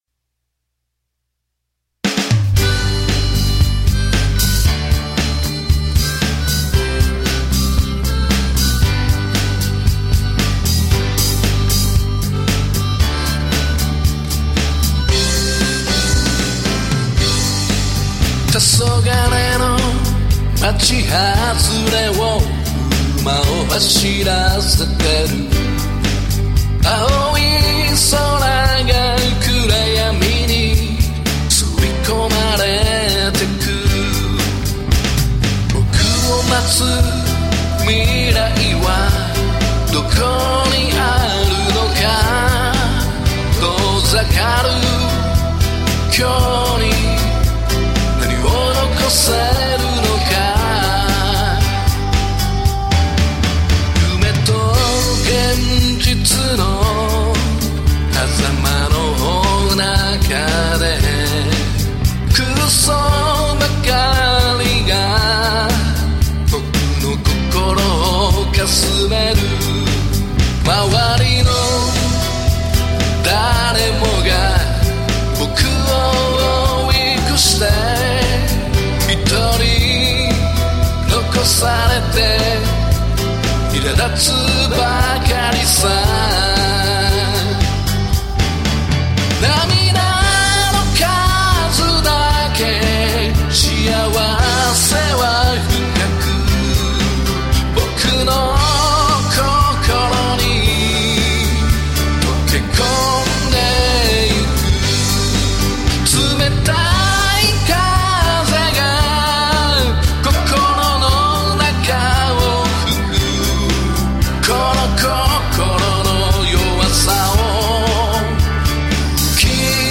しかし歌は下手くそ。
今回ギターの音色をリードもバッキングもあえて１種類シングルコイルフロントハーフの音で弾いた。
ピアノは手弾、ベースは指弾とスラップもどき、とドラムはV-Drumsで叩いた。パートも修正は最低限にした。